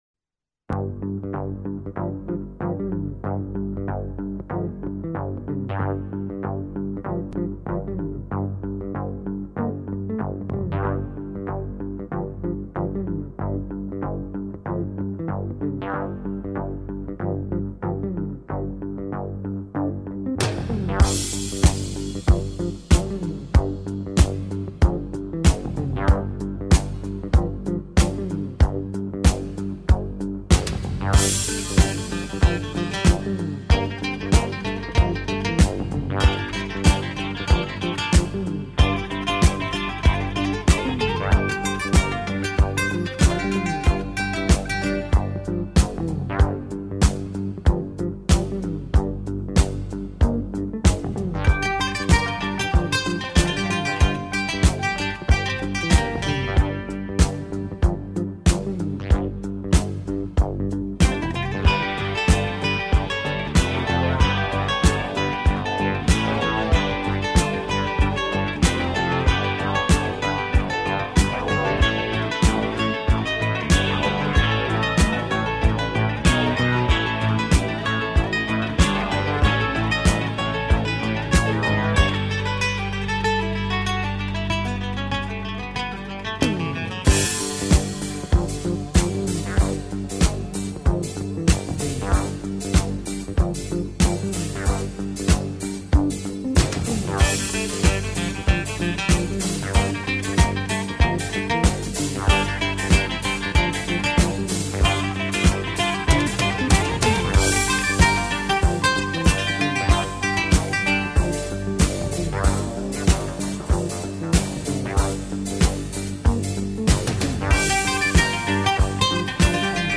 音乐中运用了多种乐器，糅合了强烈的自然声息和流行元素，风味音律流畅
欢快的节奏配以流畅的吉他弹奏，给人以蓝天流水沁人心脾的舒畅。